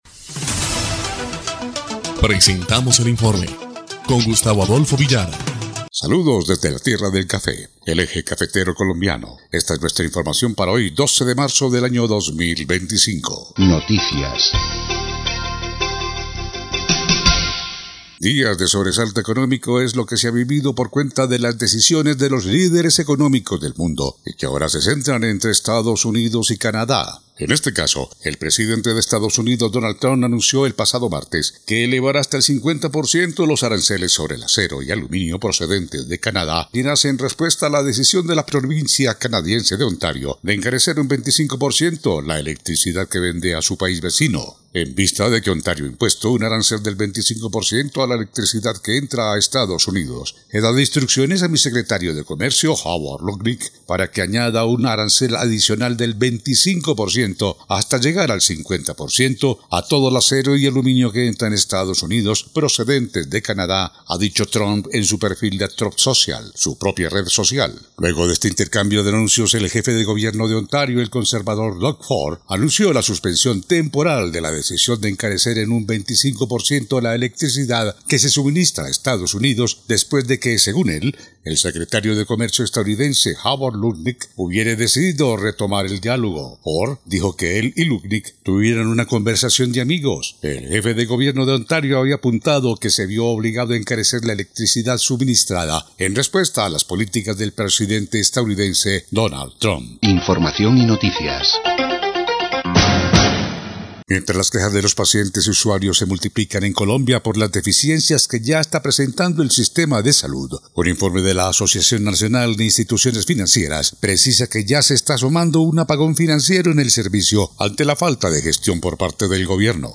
EL INFORME 1° Clip de Noticias del 12 de marzo de 2025